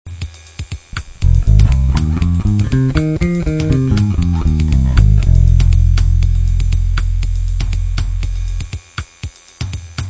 Cliquer sur Ex et vous  aurez un exemple sonore en F (Fa).
mi7.wav